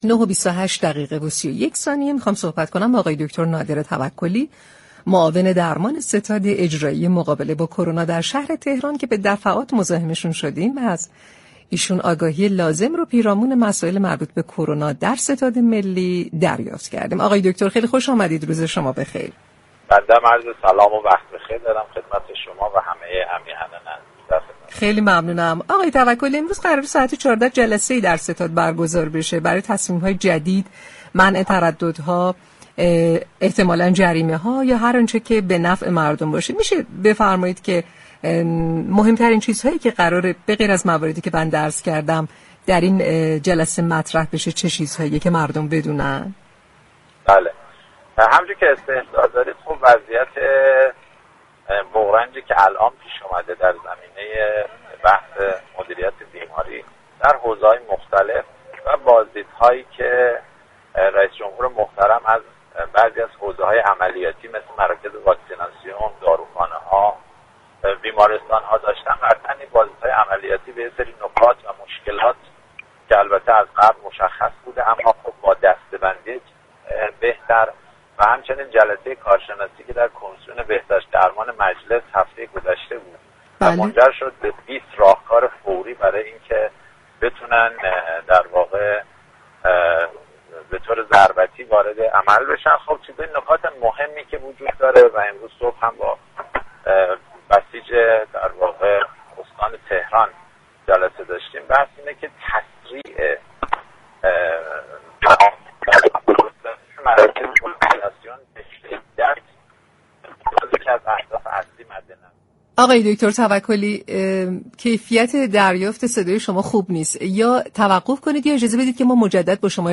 به گزارش پایگاه اطلاع رسانی رادیو تهران، دكتر نادر توكلی معاون درمان ستاد مقابله با كرونای شهر تهران در گفتگو با برنامه تهران ما سلامت درباره مهمترین مواردی كه قرار است در جلسه محدودیت های كرونایی شنبه 23 مرداد مطرح شود گفت: وضعیت بغرنجی كه در حال حاضر در زمینه مدیریت بیماری در حوزه‌های مختلف پیش آمده و بازدیدهای رئیس جمهور از بعضی حوزه‌های عملیاتی مثل مراكز واكسیناسیون، بیمارستان ها و داروخانه‌ها كه منجر به دسته‌بندی بهتری برای مقابله با كرونا می‌شود و همچنین جلسه كارشناسی كمسیون بهداشت و درمان مجلس طی هفته گذشته می‌تواند به ورود ضربتی برای كنترل این بیماری كمك كند.